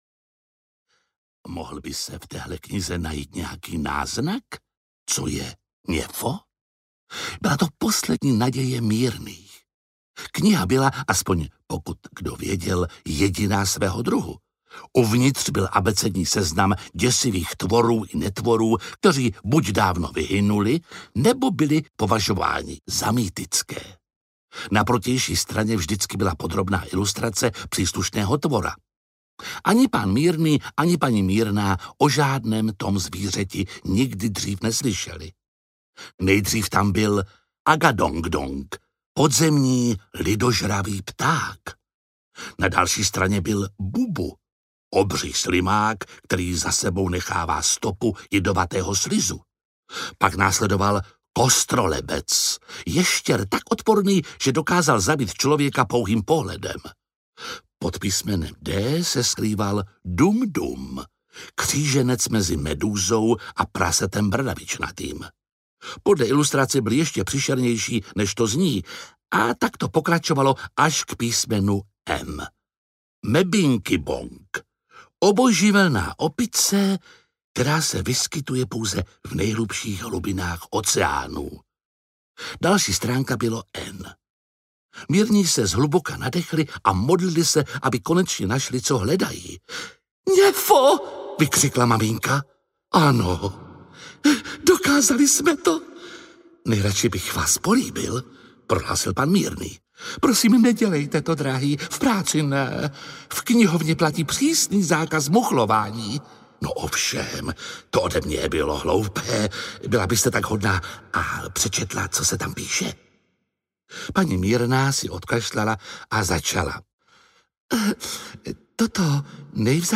Něfo audiokniha
Ukázka z knihy
• InterpretJiří Lábus